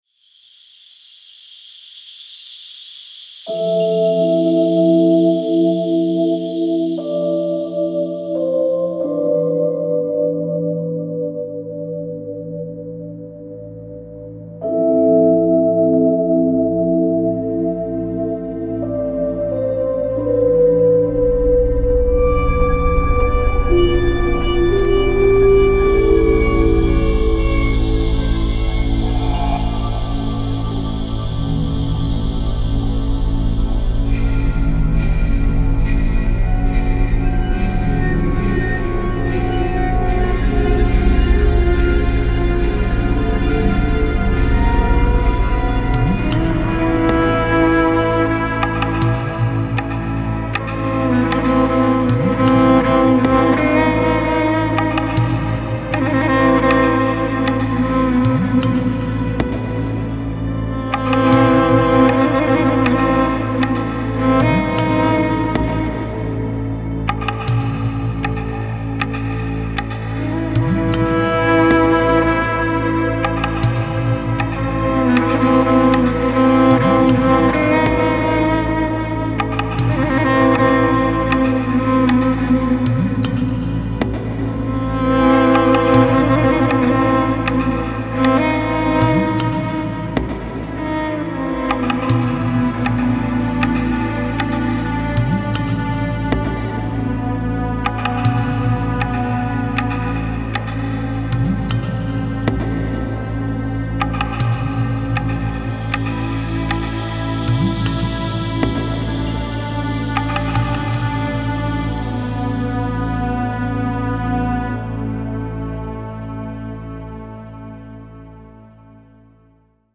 Трек размещён в разделе Турецкая музыка.